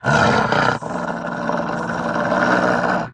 bdog_groan_2.ogg